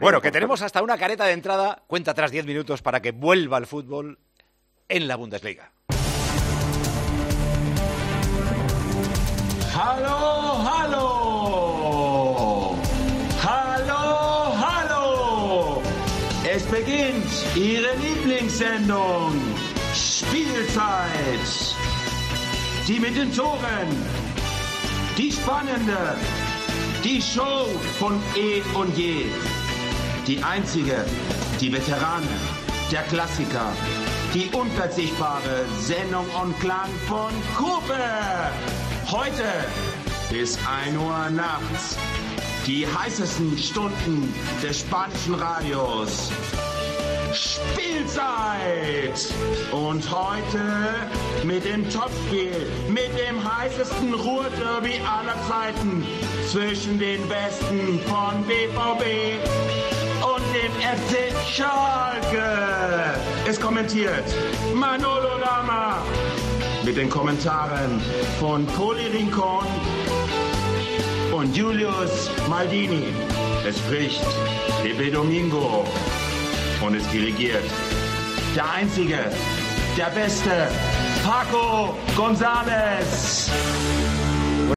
Vuelve el fútbol en Alemania, vuelve la Bundesliga y para celebrarlo hemos hecho la tradicional careta de Tiempo de Juego en alemán.